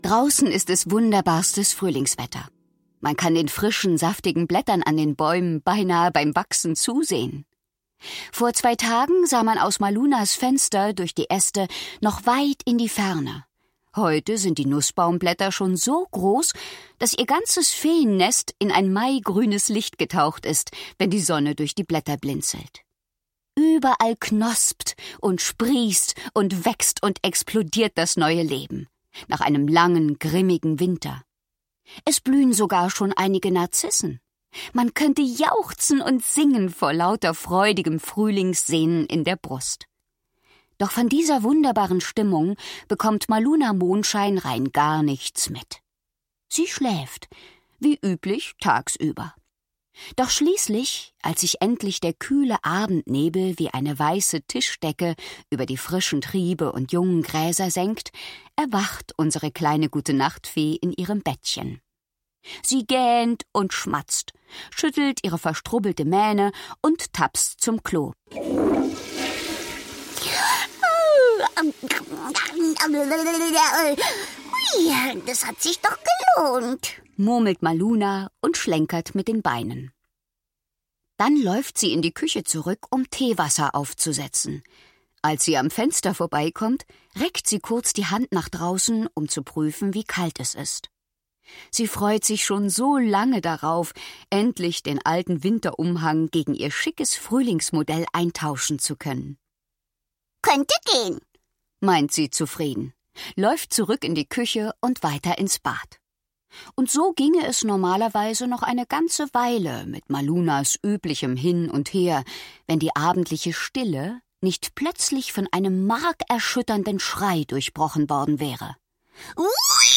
Schlagworte Feen • Feen; Kinder-/Jugendliteratur • Gutenacht-Geschichten • Gute-Nacht-Geschichten • Hörbuch; Lesung für Kinder/Jugendliche • Zauberei